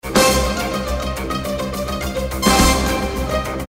Всем доброго время суток) Отцы подскажите пожалуйста в каких Vsti клавах такой пресет? на фоне звука мандалины, взрывные звуки оркестра?